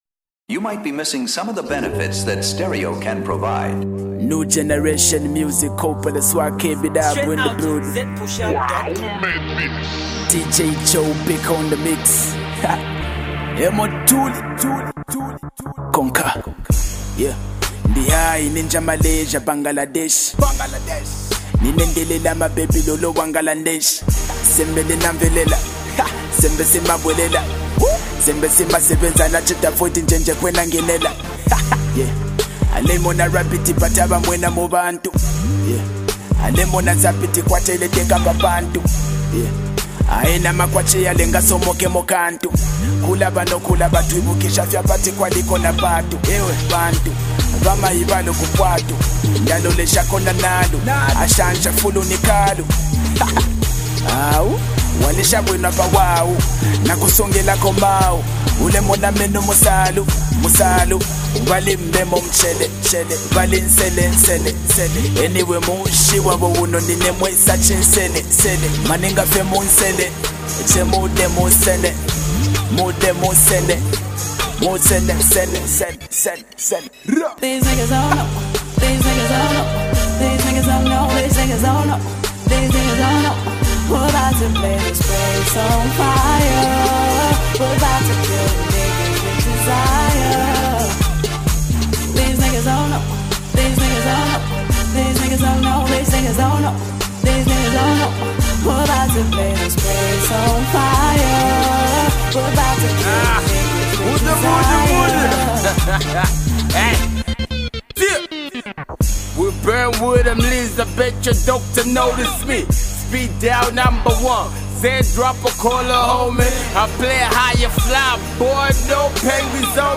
Kabwe based rapper